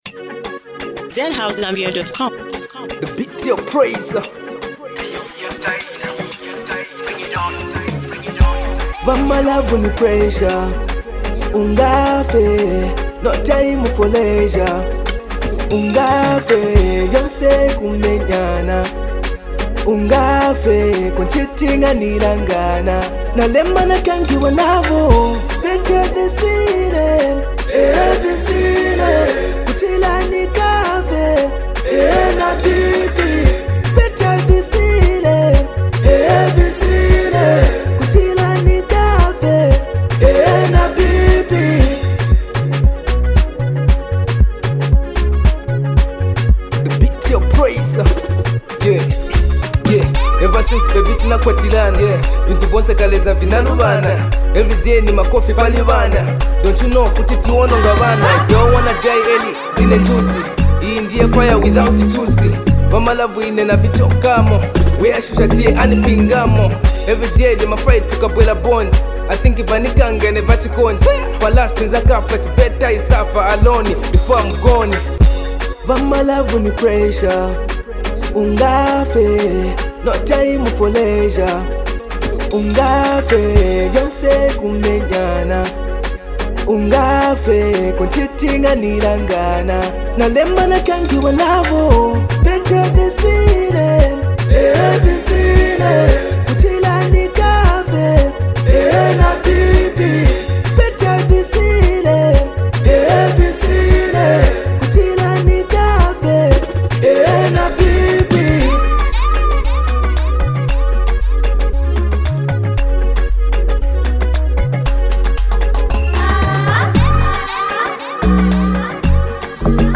Through heartfelt lyrics and a gripping beat